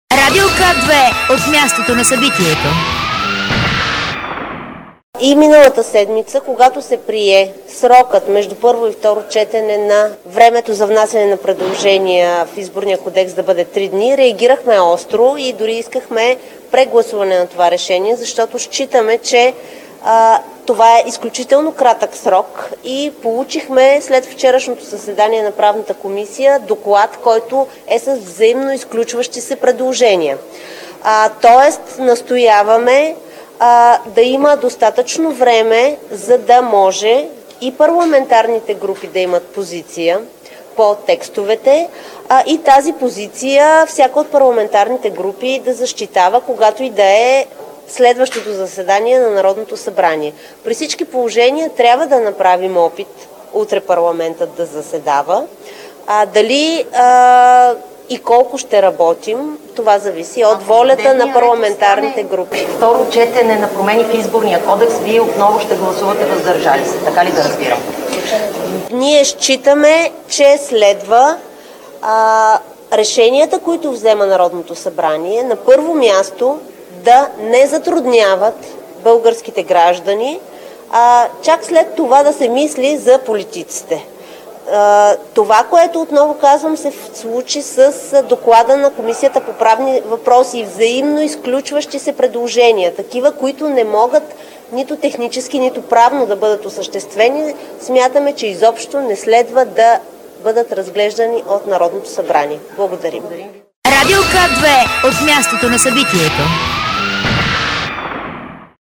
10.10 - Брифинг на Тома Биков от ПГ на ГЕРБ. - директно от мястото на събитието (Народното събрание)
Директно от мястото на събитието